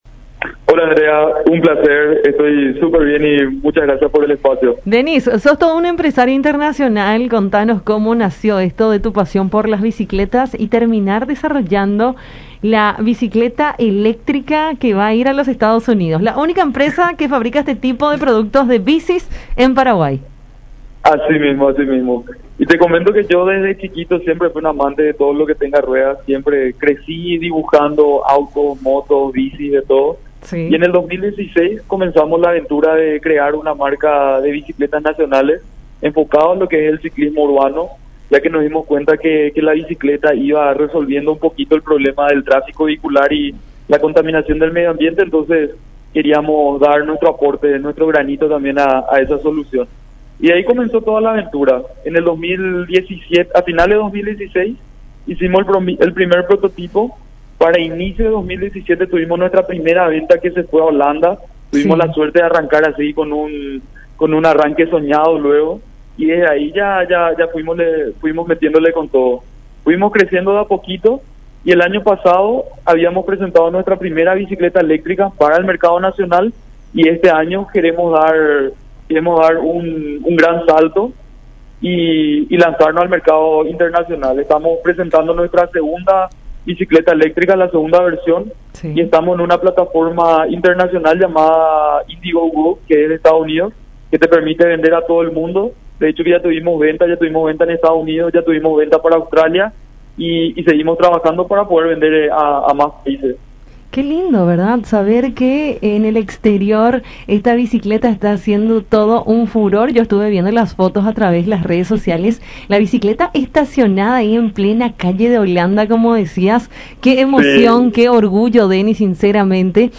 en conversación con Enfoque 800 por La Unión